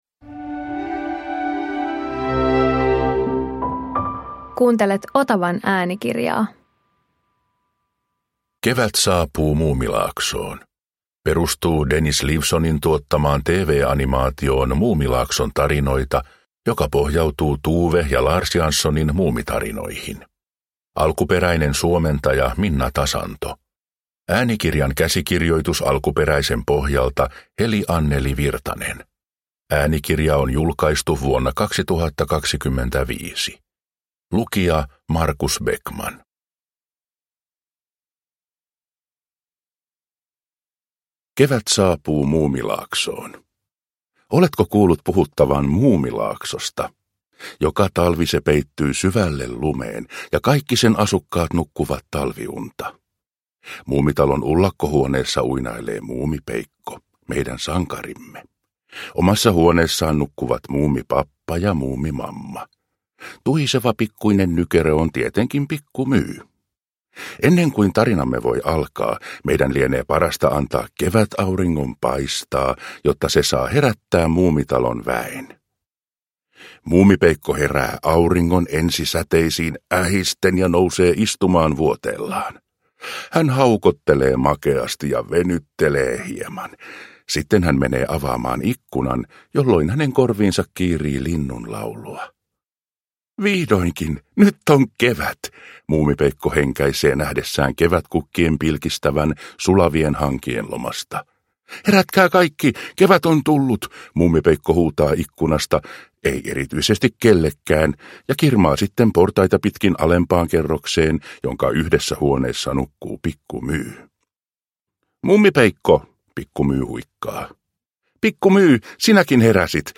Muumi - Kevät saapuu Muumilaaksoon – Ljudbok